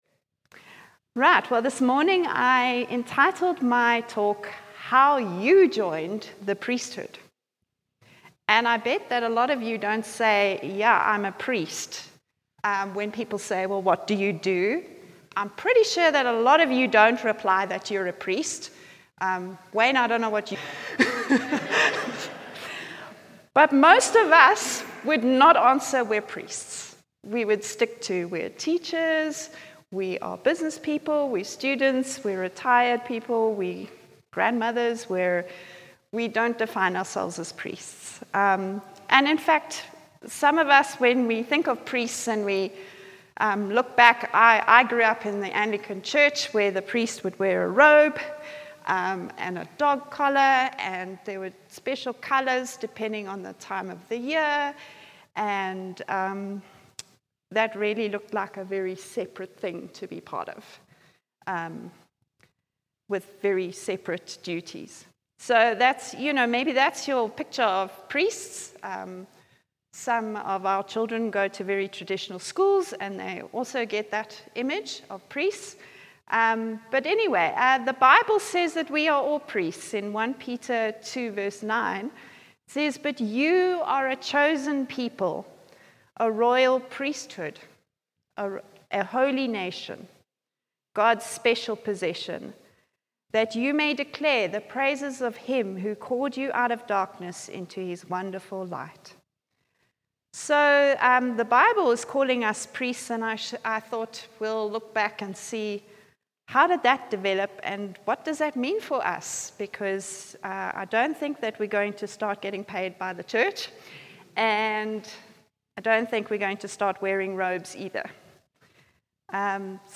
From Hillside Vineyard Christian Fellowship, at Aan-Die-Berg Gemeente. Johannesburg, South Africa.